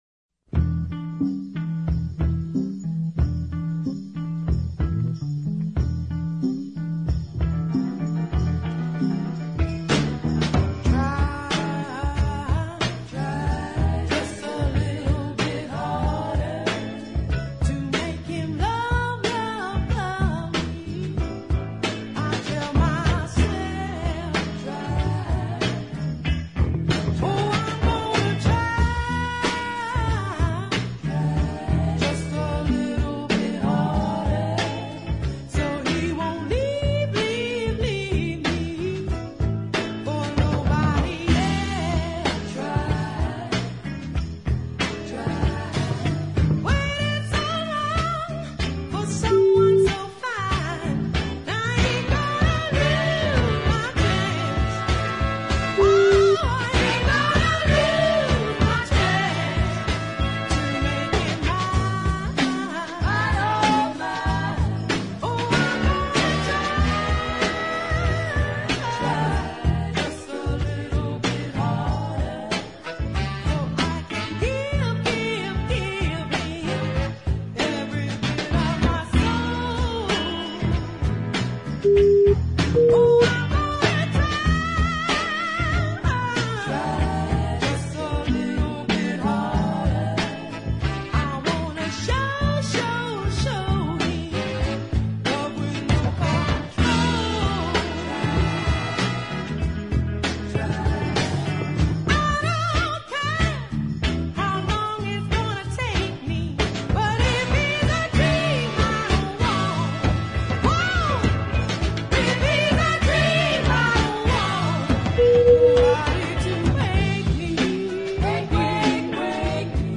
No full orchestra or grandiose arrangements are in sight.
slow-to-mid-paced lazy funk
oh-so-soulful delivery